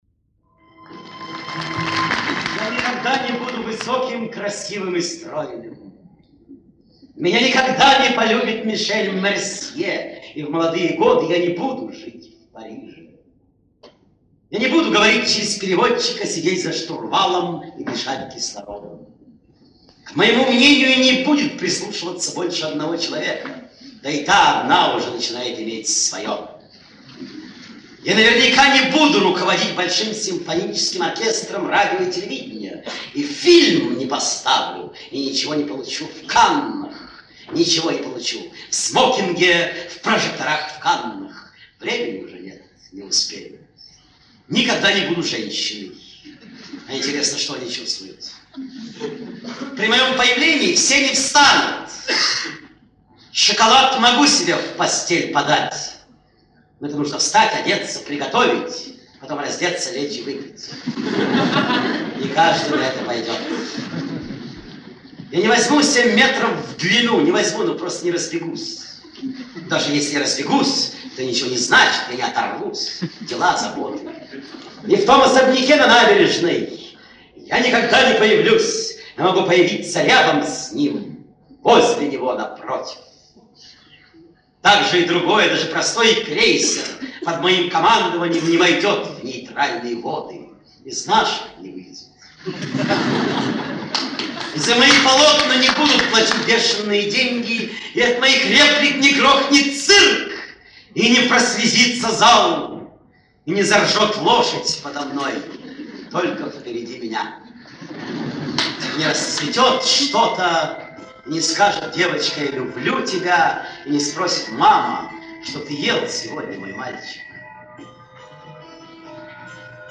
Извините, но качество слабое.